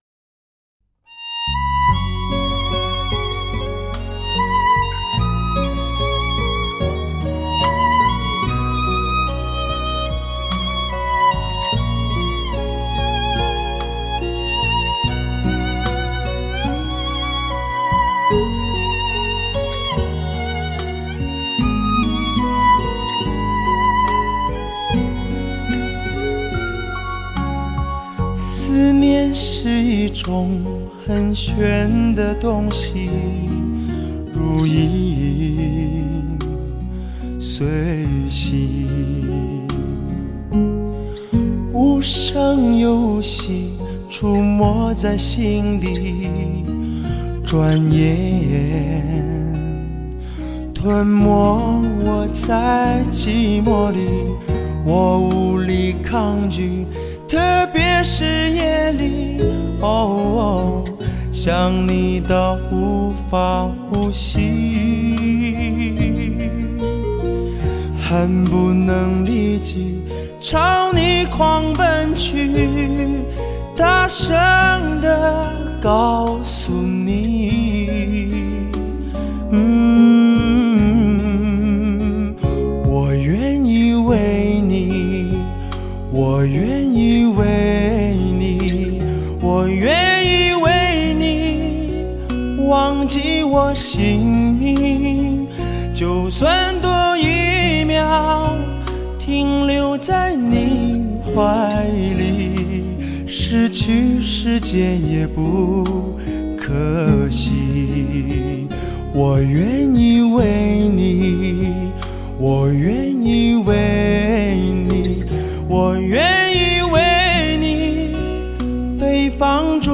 德国黑胶CD
兼容黑胶的高保真和CD的低噪音